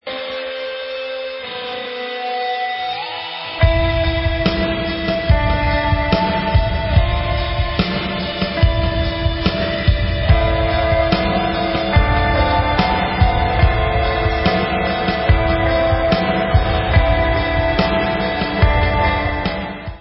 POP WAVE